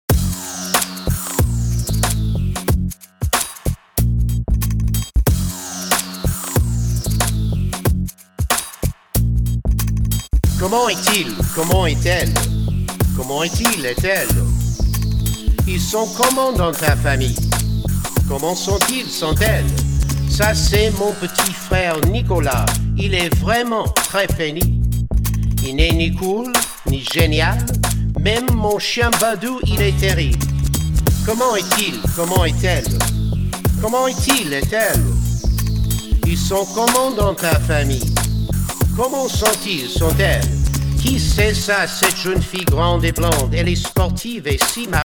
French Language Raps
Ten original raps on CD.